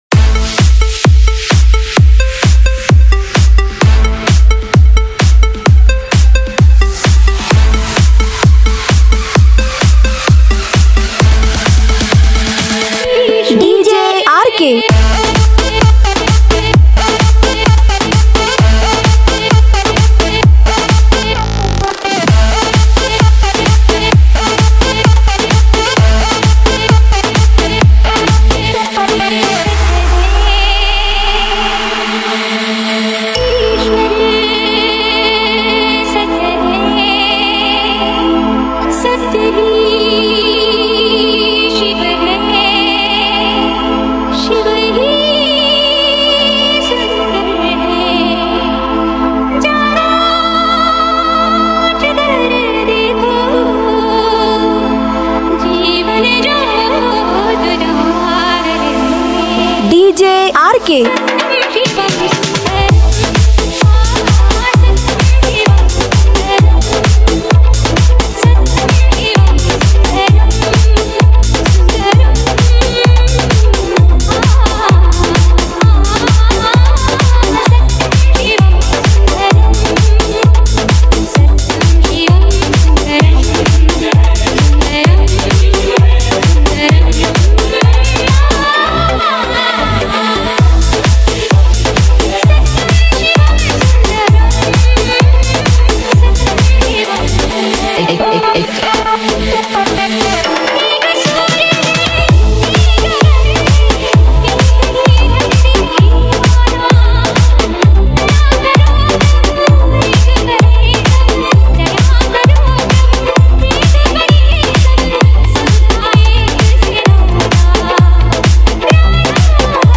Shivratri Special DJ Remix Songs Songs Download